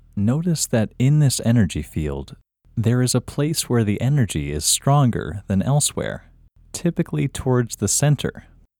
IN – Second Way – English Male 4